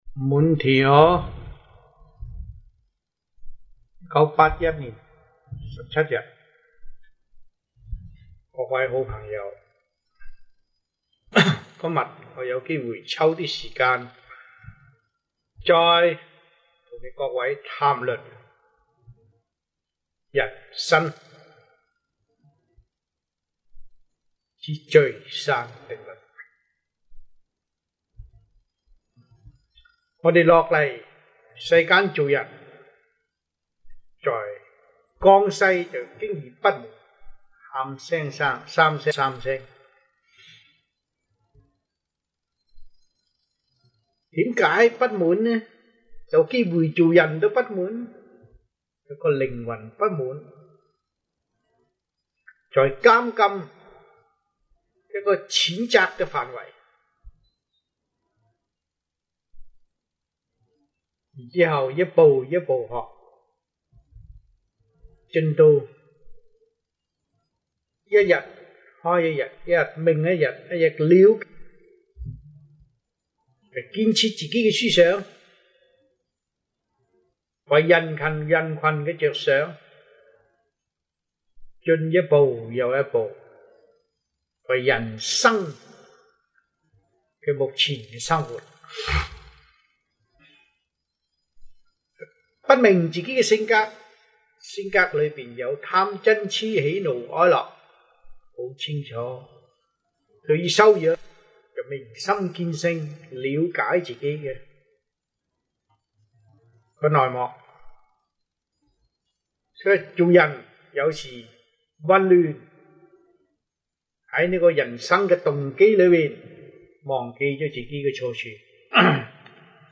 Lectures-Chinese-1981 (中文講座)